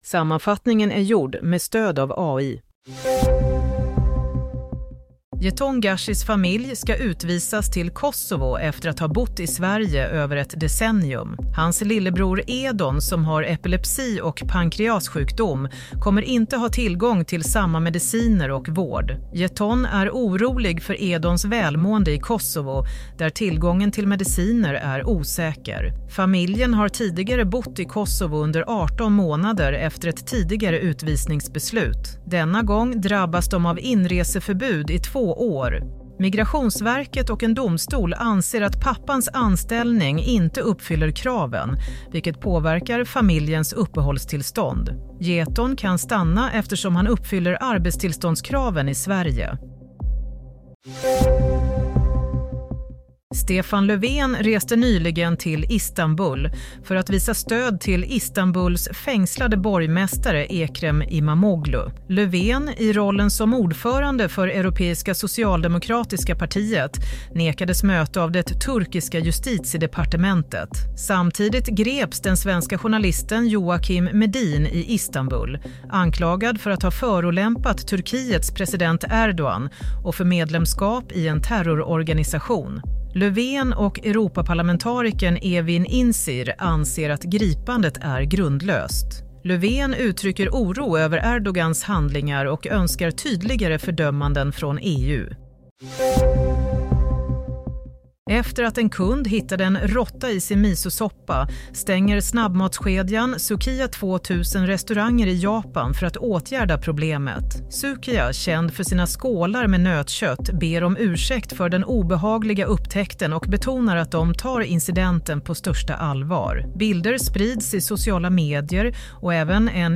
Nyhetssammanfattning - 29 mars 22:00